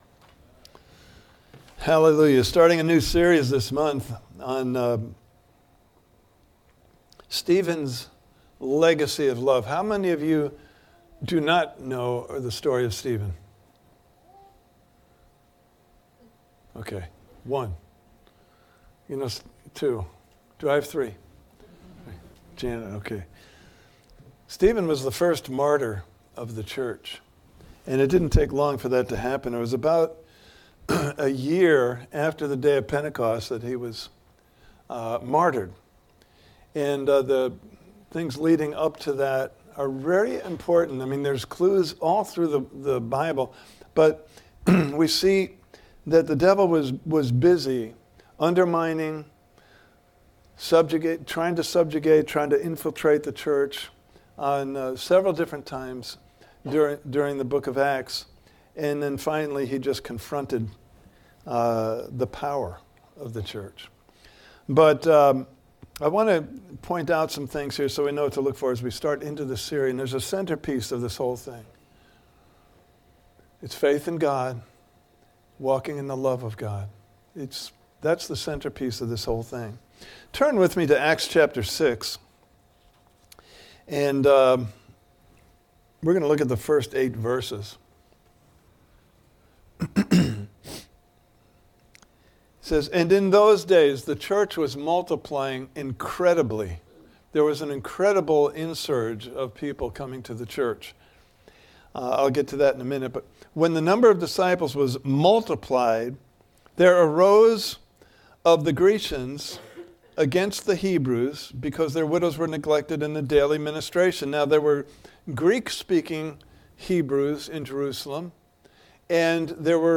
Stephen’s Legacy of Love Service Type: Sunday Morning Service « Part 4